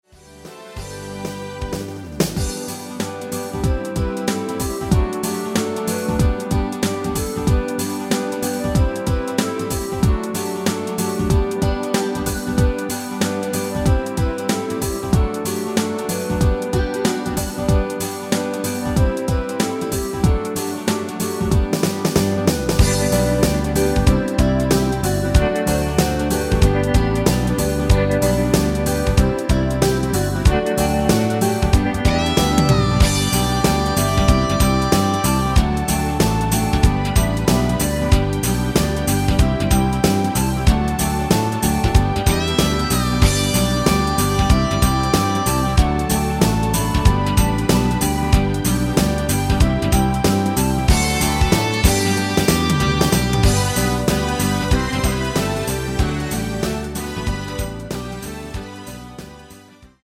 원키 멜로디 포함된 MR 입니다.
노래방에서 노래를 부르실때 노래 부분에 가이드 멜로디가 따라 나와서
앞부분30초, 뒷부분30초씩 편집해서 올려 드리고 있습니다.
중간에 음이 끈어지고 다시 나오는 이유는